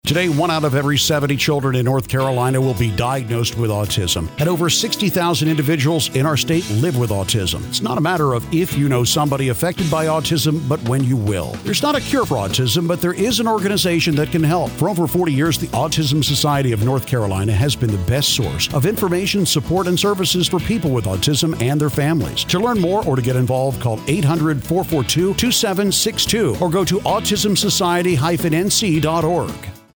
PSAs
Radio Spots